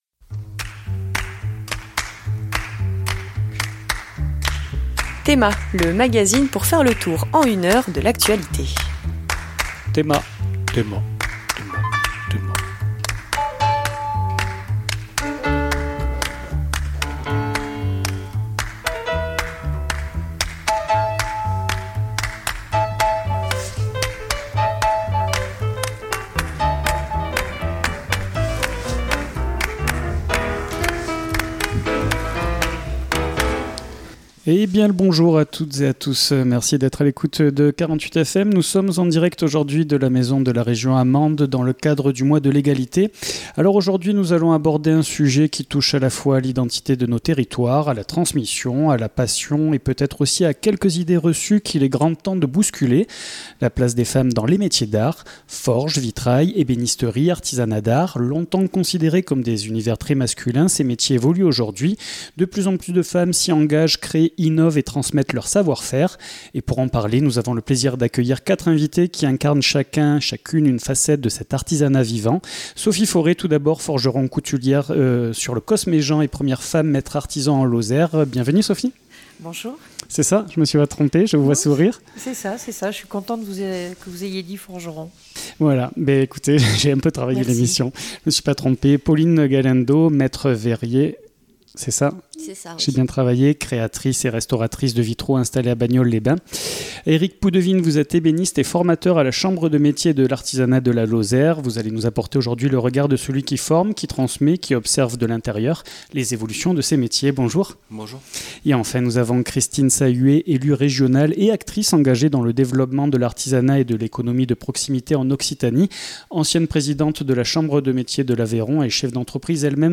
Emission du mardi 17 mars 2026 en direct de la maison de la région